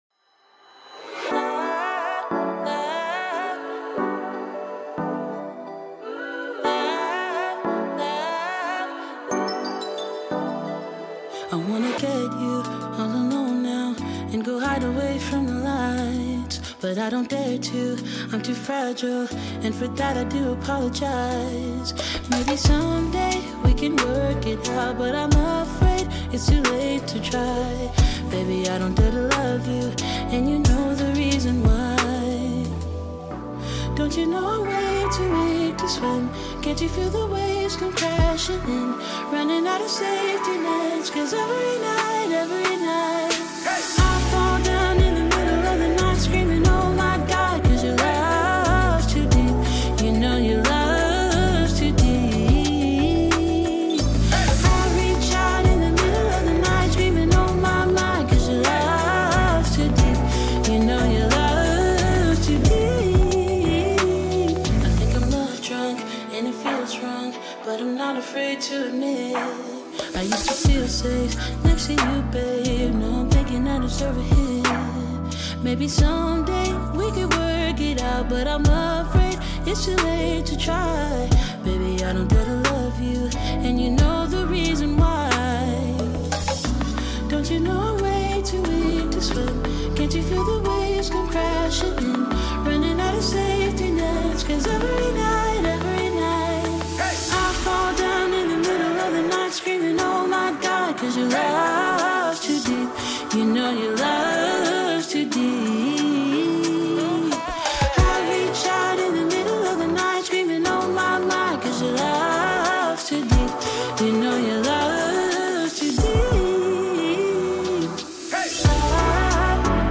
Study Sounds, Background Sounds, Programming Soundscapes, Coding Beats 1 Hour Study Endurance Timer for Laser Focus May 11 2025 | 01:00:26 1x 00:00 / 01:00:26 Subscribe Share RSS Feed Share Link Embed